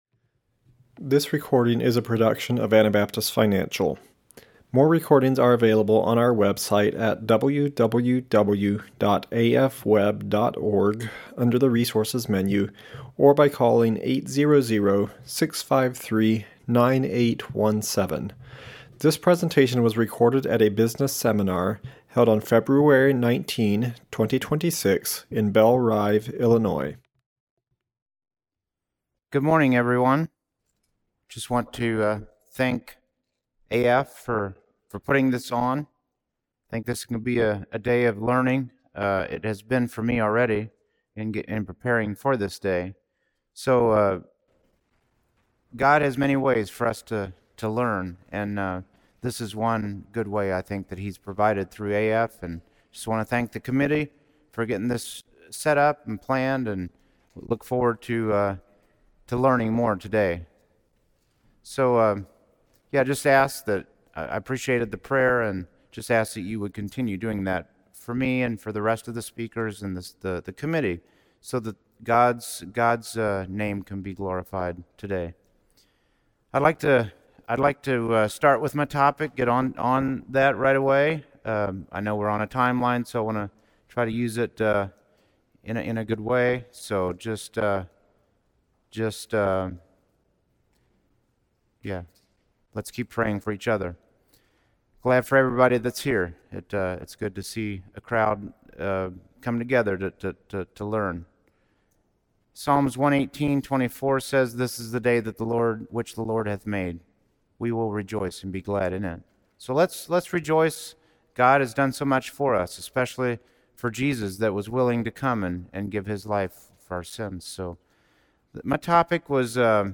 Illinois Business Seminar 2026